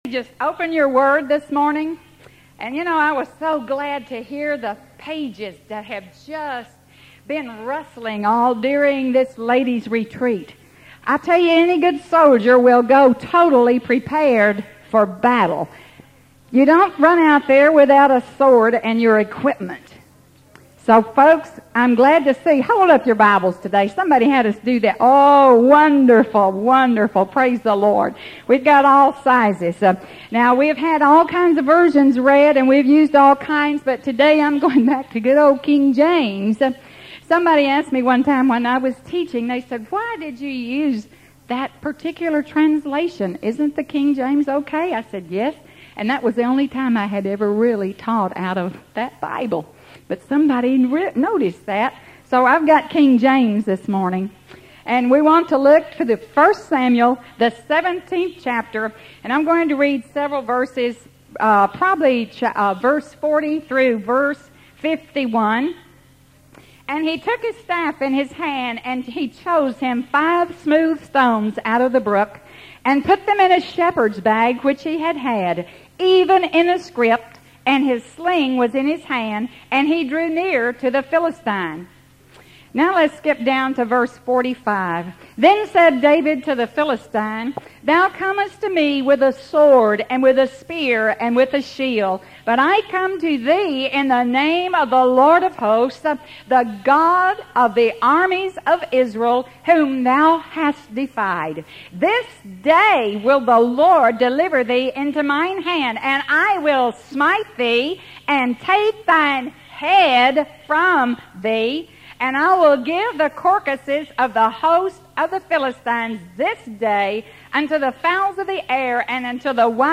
Women Preachers